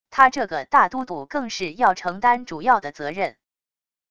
他这个大都督更是要承担主要的责任wav音频生成系统WAV Audio Player